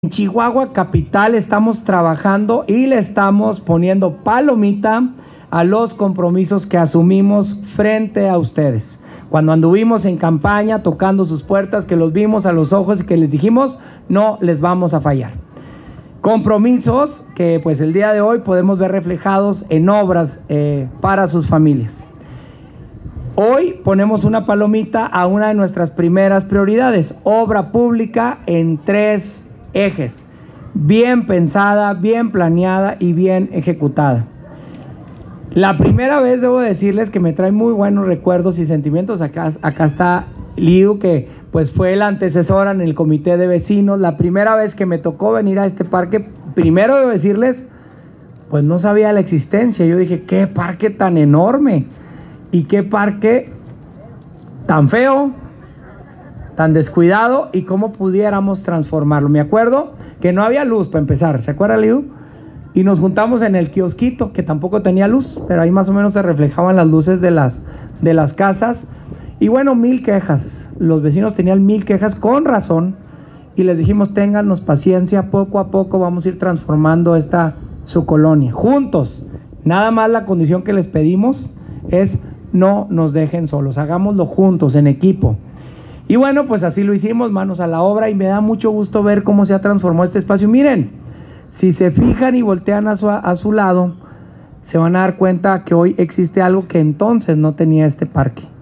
Audio. Alcalde capitalino Marco Bonilla Mendoza.
Bonilla-sobre-repavimentacion.mp3